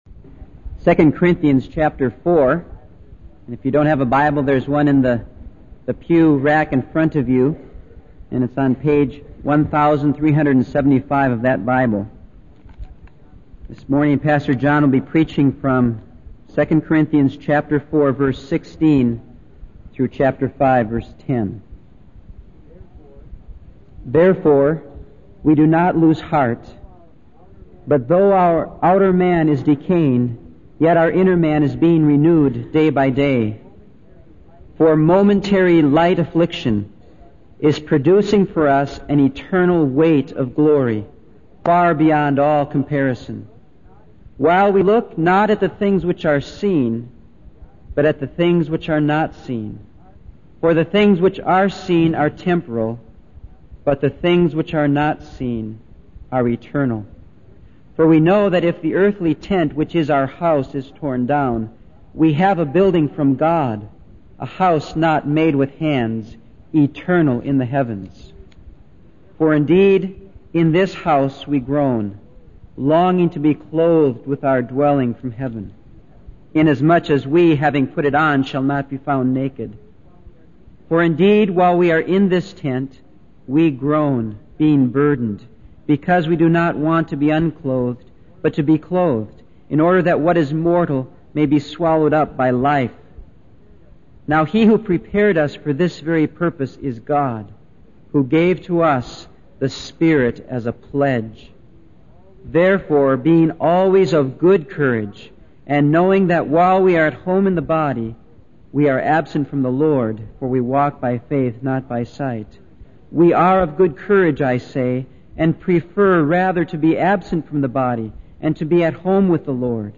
In this sermon series, the preacher emphasizes the importance of living out the word of God in our daily lives.